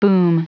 Prononciation du mot boom en anglais (fichier audio)
Prononciation du mot : boom